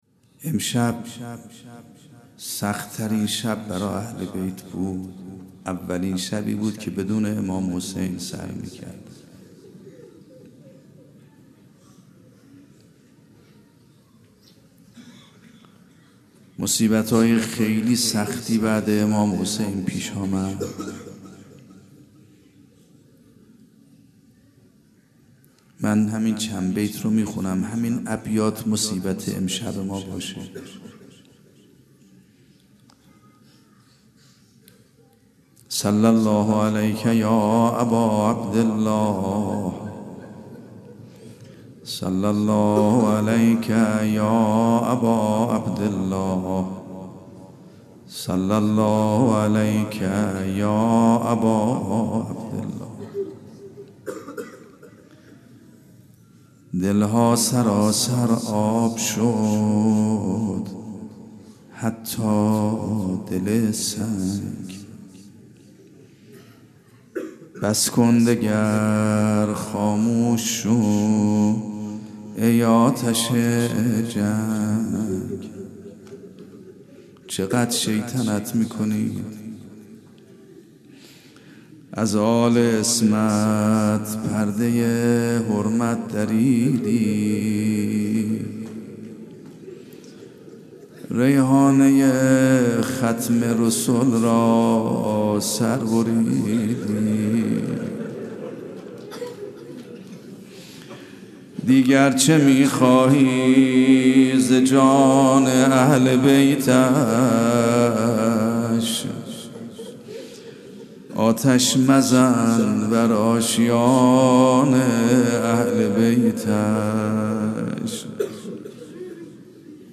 آنچه پیش رو دارید یازدهمین جلسه سخنرانی آیت الله سید محمد مهدی میرباقری؛ رئیس فرهنگستان علوم اسلامی قم است که در دهه اول محرم الحرام سال ۱۳۹۷در هیأت ثارالله قم (مدرسه فیضیه) برگزار شده است.
roze.mp3